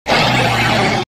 Cri de Lougaroc (Forme Crépusculaire) dans Pokémon Ultra-Soleil et Ultra-Lune.